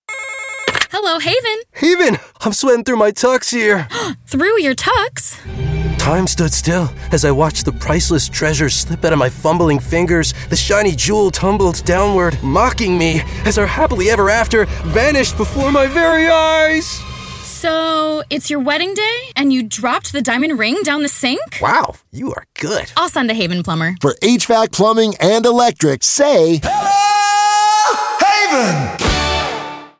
RADIO: "WEDDING RING"